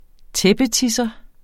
Udtale [ ˈtεbəˌtisʌ ]